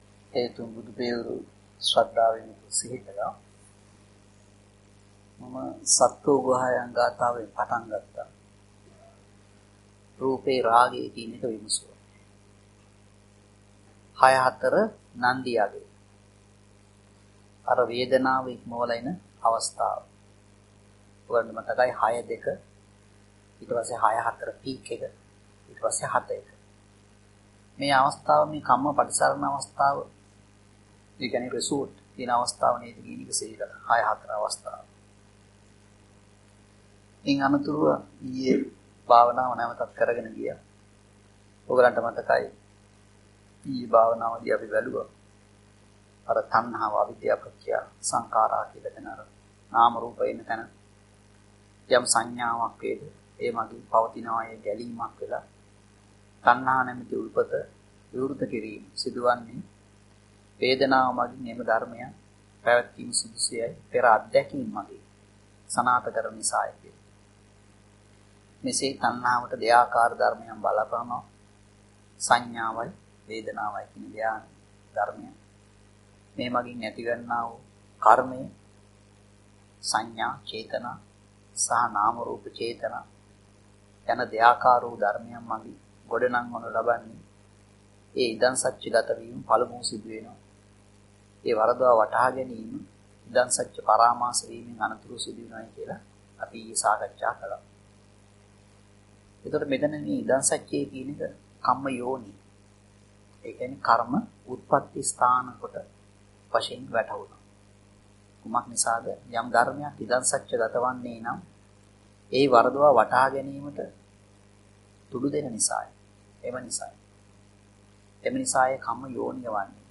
Talk Diagram Transcribed